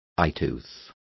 Complete with pronunciation of the translation of eyeteeth.